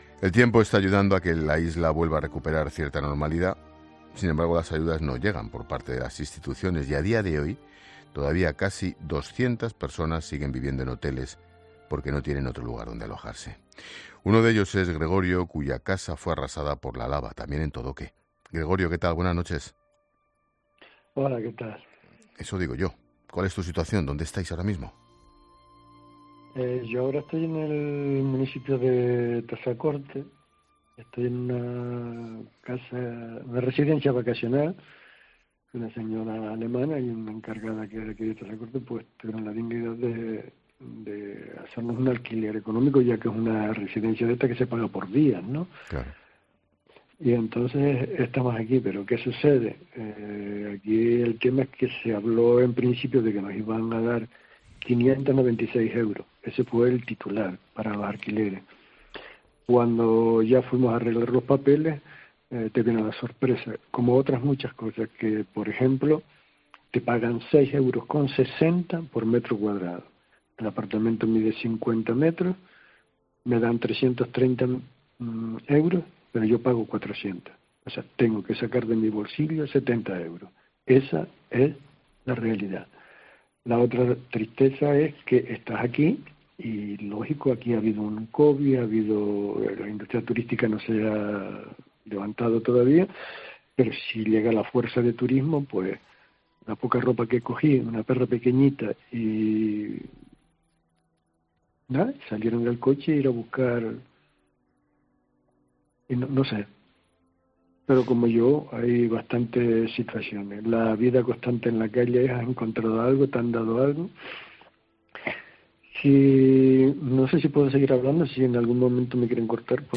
El 20 de septiembre, Expósito y su equipo regresaban a la isla, cuando se cumplía un año del comienzo de la erupción, para contar, sobre el terreno cuál es la situación
'La Linterna' desde La Palma un año después